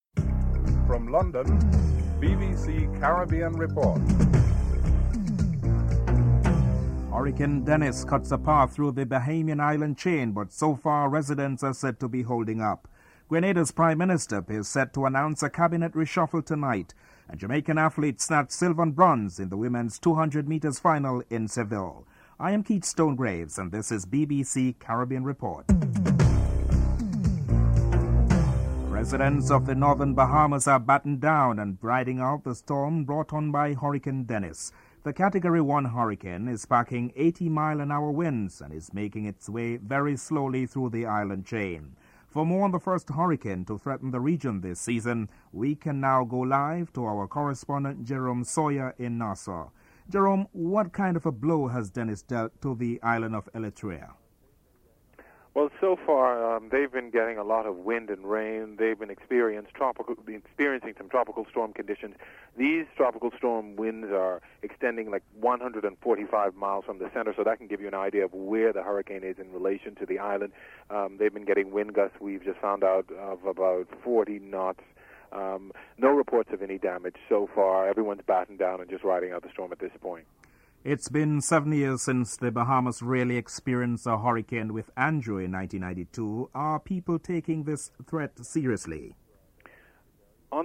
Peter Greste reports on the clashes (09:24 – 11:10)
BBC correspondents invite reflections and insights on Notting Hill Carnival from older participants in the festival (13:36 – 15:30)